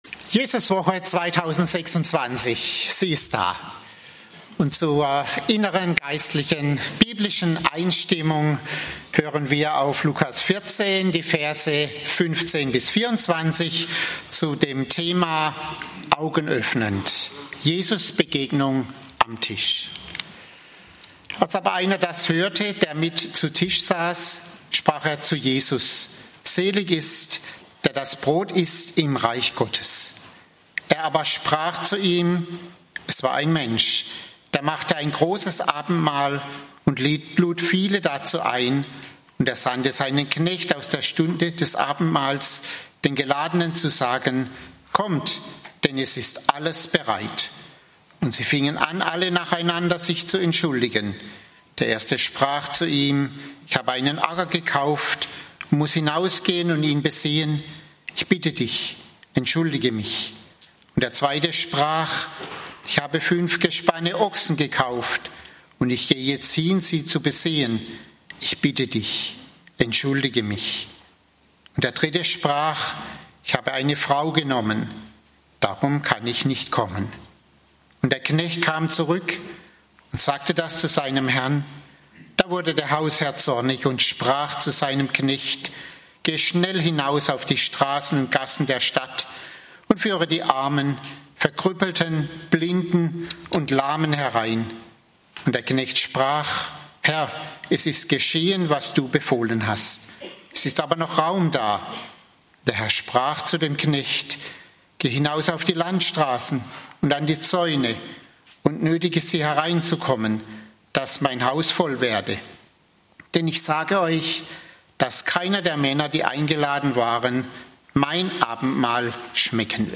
Jesuswoche 2026 - "Augenöffnend": Jesusbegegnung bei Tisch (Lk. 14, 15-24) - Gottesdienst